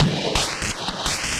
Index of /musicradar/rhythmic-inspiration-samples/170bpm